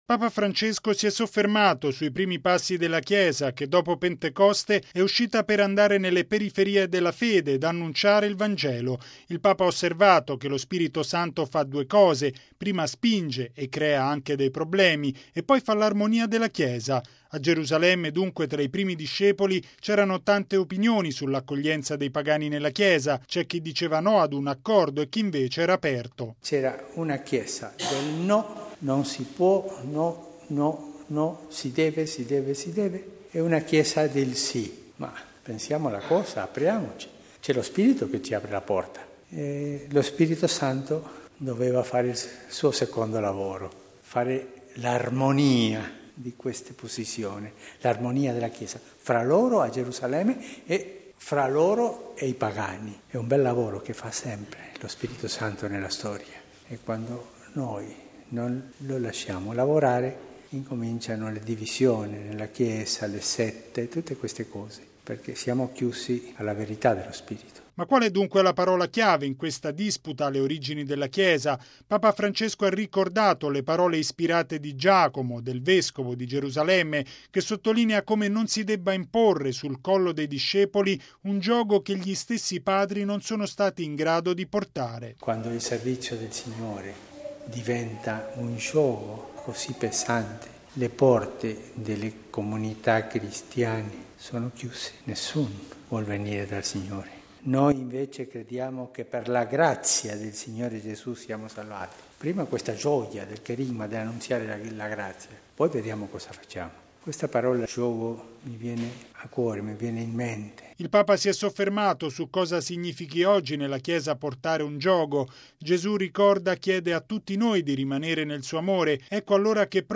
E’ quanto affermato, stamani, da Papa Francesco nella Messa celebrata nella Cappella della Casa Santa Marta. Il Papa ha sottolineato che quando i cristiani non fanno lavorare lo Spirito Santo allora cominciano le divisioni nella Chiesa.